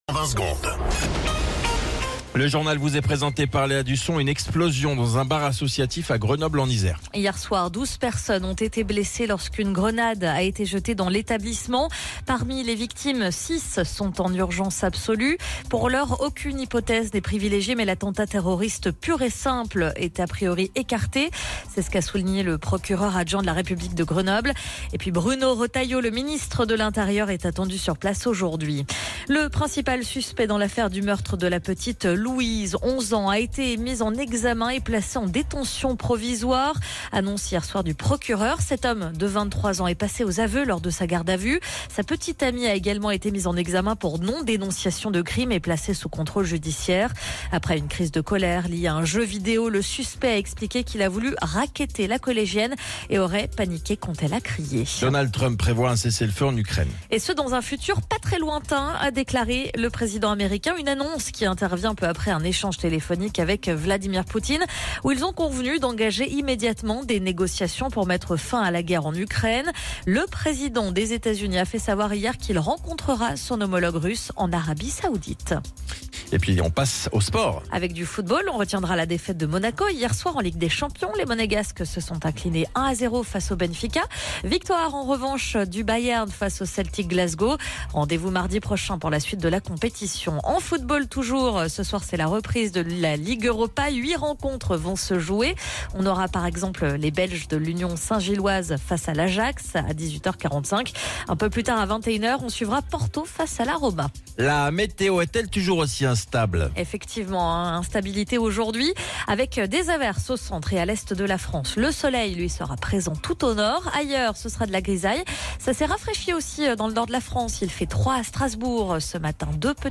Flash Info National 13 Février 2025 Du 13/02/2025 à 07h10 .